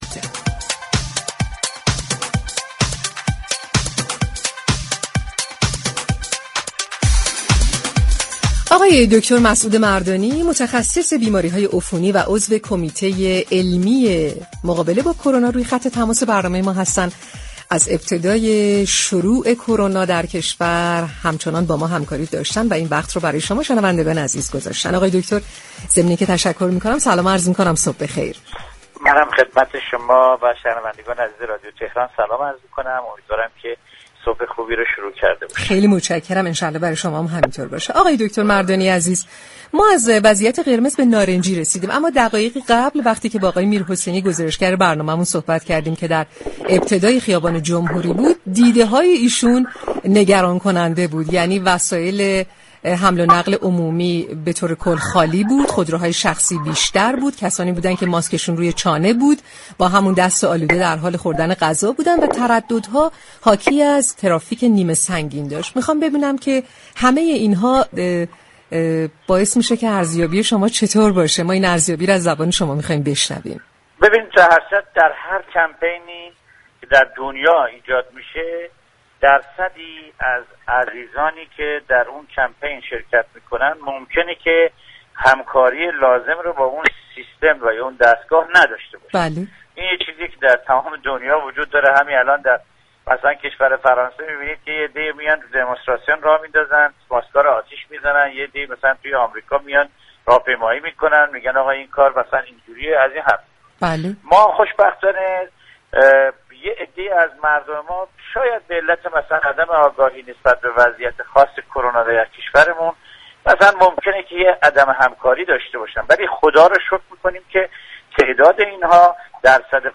به گزارش پایگاه اطلاع رسانی رادیو تهران، مسعود مردانی در گفتگو با برنامه تهران كلینیك رادیو تهران با اشاره وضعیت بحرانی تهران و عدم رعایت پروتكل های بهداشتی توسط برخی از شهروندان گفت: در هر پویشی در دنیا برخی همكاری لازم را با سیستم ندارند به عنوان مثال در كشورهای فرانسه و آمریكا شاهد اعتراض برخی نسبت به پروتكل های بهداشتی هستیم ولی در كشور ما برخی از هموطنان به دلیل آگاهی كم نسبت به این بیماری همكاری كمتری دارند اما تعداد این افراد كم و قابل بررسی است.